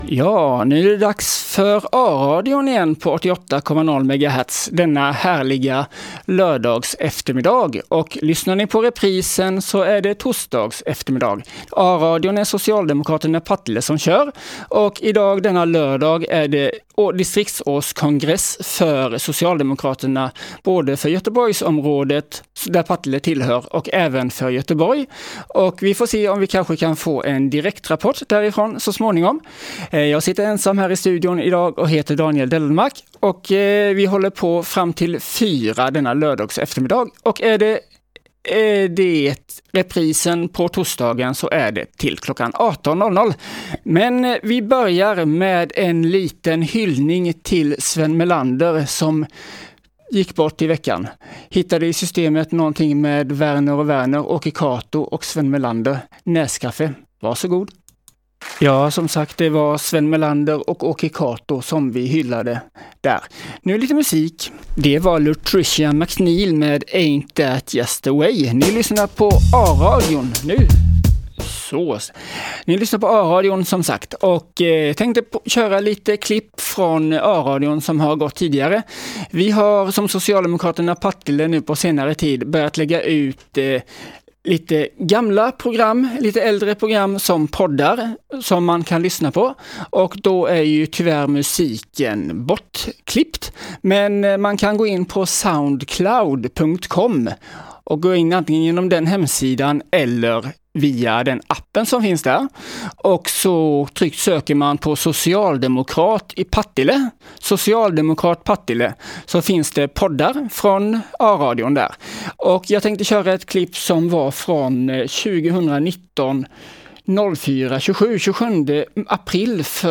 A-radion 2022-04-03 pod Sap Partille söndag 3 april 2022 Denna sändning innehåller klipp från tidigare sändningar i a-radion för att tipsa om våra program på SoundCloud, Musiken med upphovsrätt är bortklippt av upphovsrättsliga skäl.